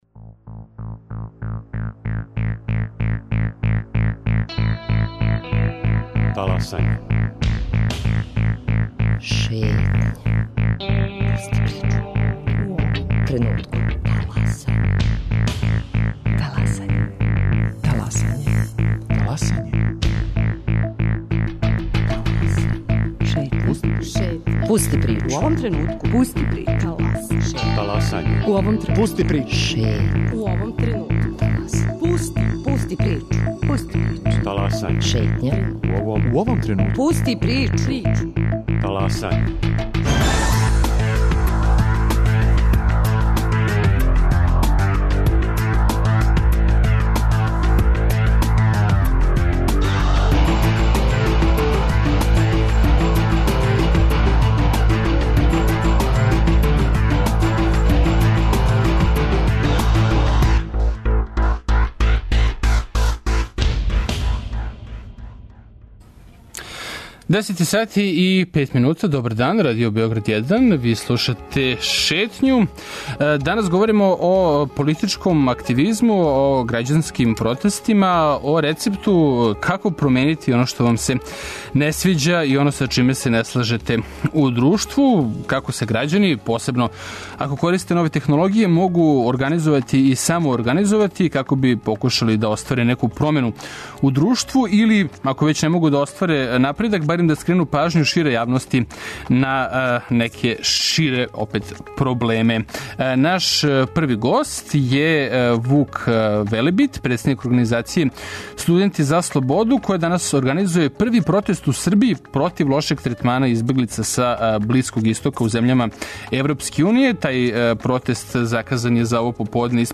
У студио нам потом долази Срђа Поповић, један од најпризнатијих светских стручњака за организацију ненасилних протеста, који на Харвард универзитету води студијски програм управо на ову тему.